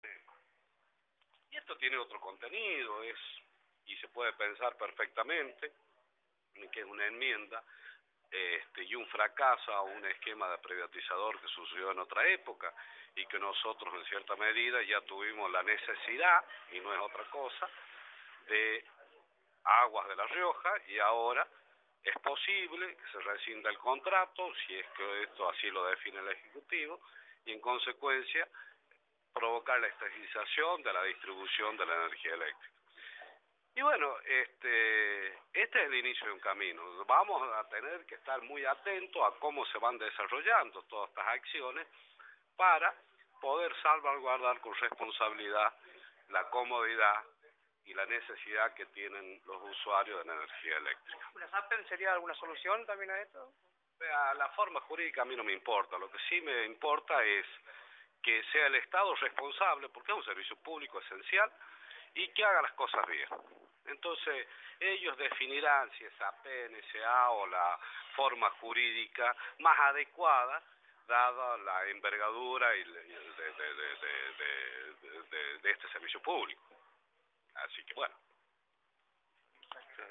Mario Guzmán Soria, diputado, por Cadena 3 Argentina